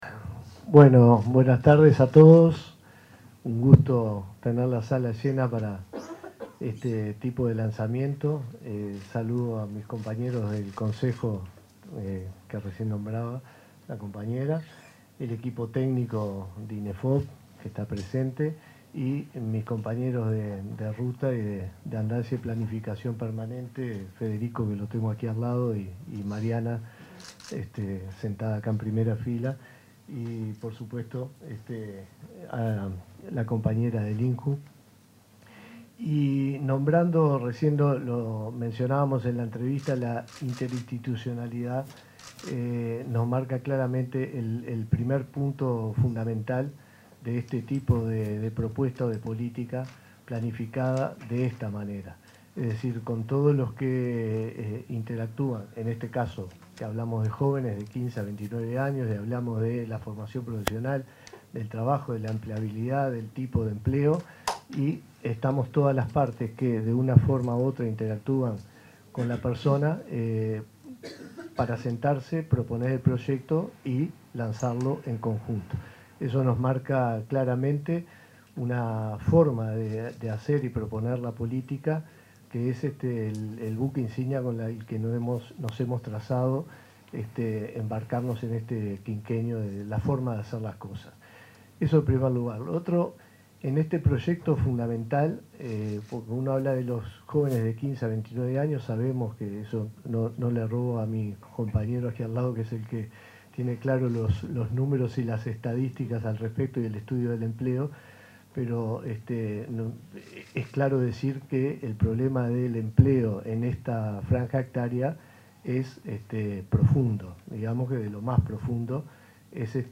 Palabras de autoridades en lanzamiento de convocatoria de formación profesional
Palabras de autoridades en lanzamiento de convocatoria de formación profesional 15/09/2025 Compartir Facebook X Copiar enlace WhatsApp LinkedIn El director general del Instituto Nacional de Empleo y Formación Profesional, Miguel Venturiello; el director nacional de Empleo, Federico Araya, y la directora del Instituto Nacional de la Juventud, Eugenia Godoy, presentaron una convocatoria de formación profesional para generar propuestas destinadas a jóvenes.